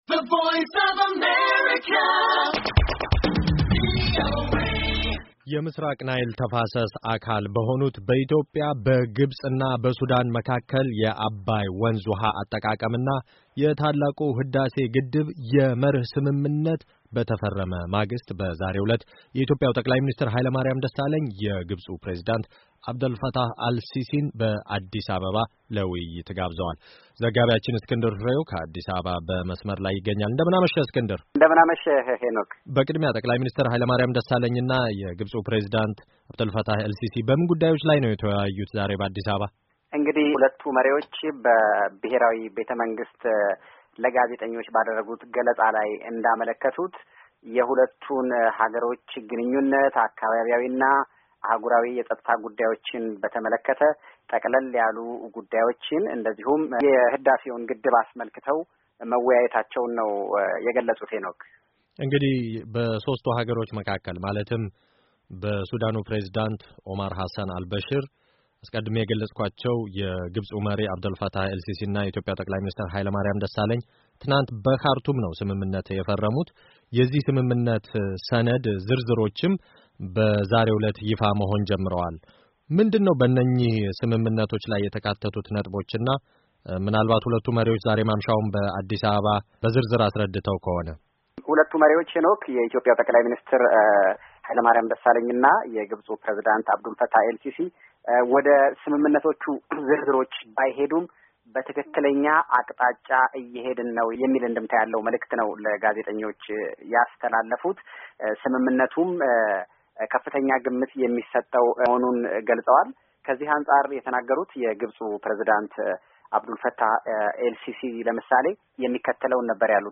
የአብዱልፋታህ ኤል-ሲሲና የኃይለማርያም ደሣለኝ መግለጫ - ዘገባ